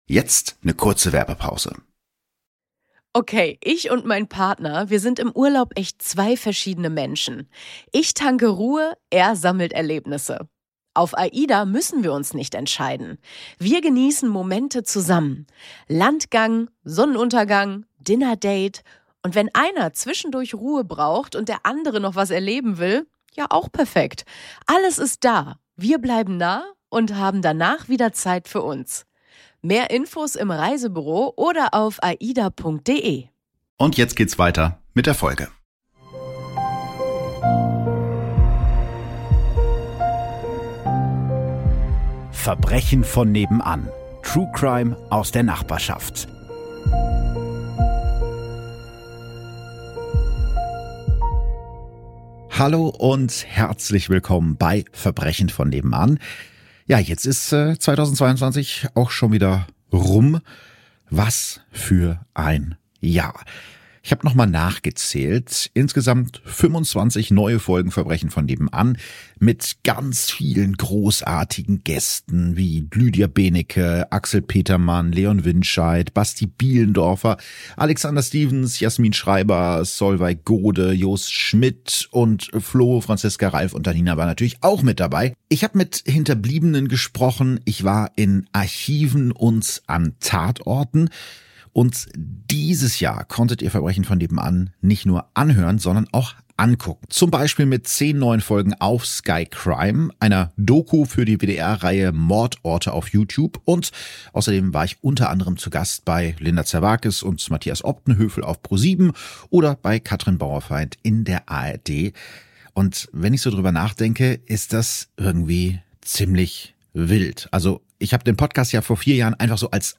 Zum Abschluss des Jahres 2022 hört ihr das Finale der Live-Tour von „Verbrechen von Nebenan“ aus dem ausverkauften Theater am Aegi in Hannover.